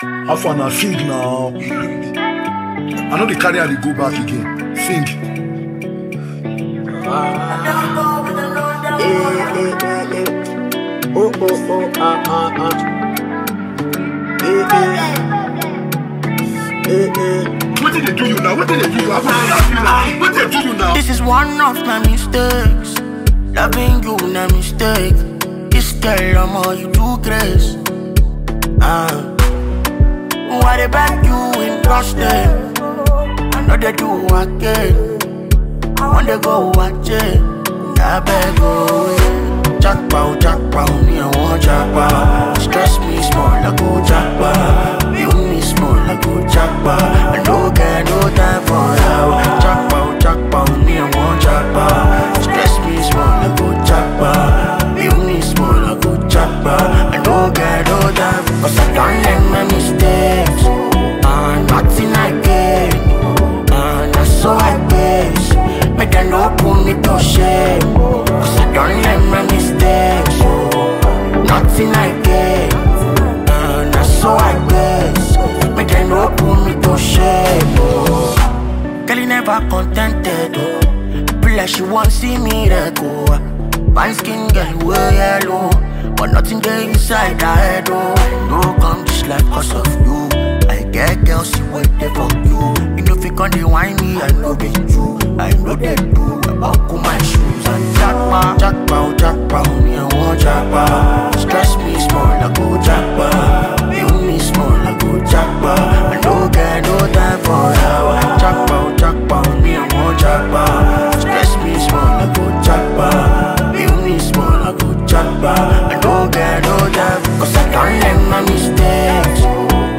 single
is a soulful and catchy tune that blends emotion with rhythm
With smooth vocals and relatable lyrics